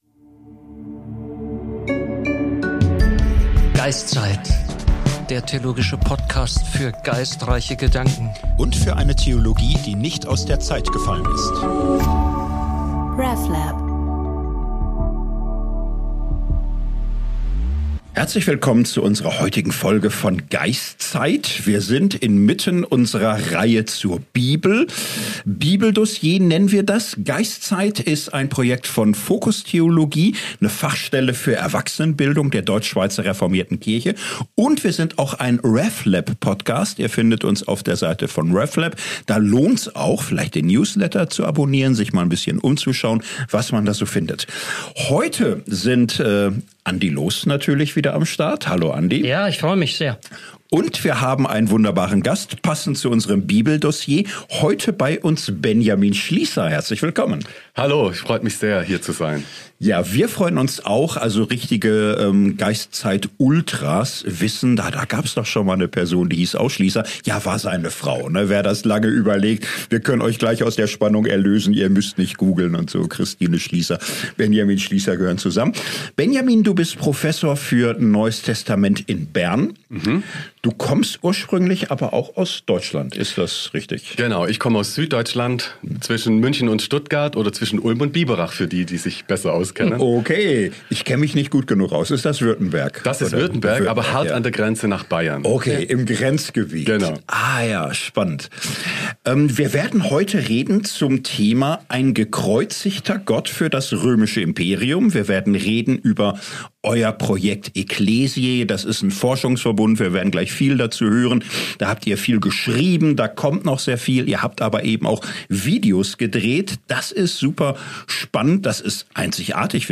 Im Gespräch wollen wir es nun auch wissen: Wie konnte sich eine religiöse Gruppe durchsetzen, die mit ihrer Verehrung eines von den Römern gekreuzigten Juden alle vor den Kopf stossen musste?